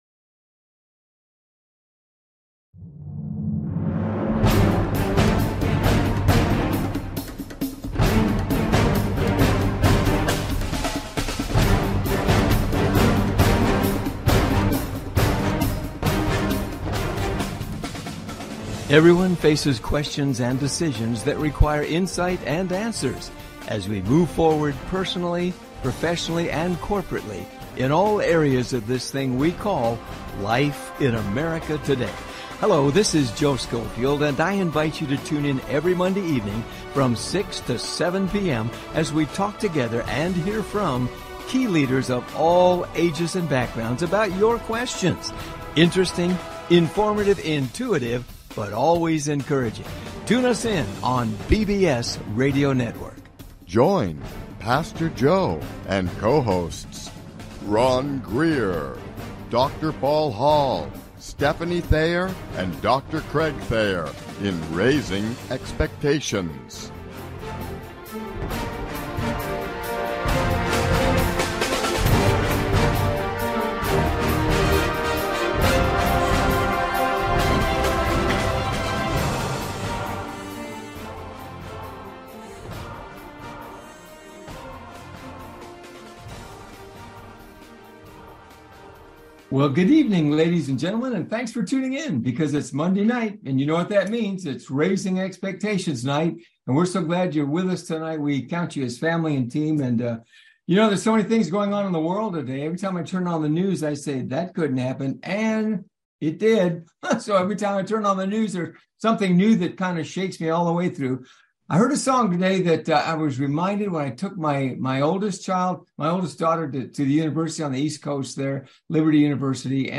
Raising Expectations Talk Show